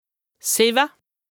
2APRESTA_OLCA_LEXIQUE_INDISPENSABLE_HAUT_RHIN_88_0.mp3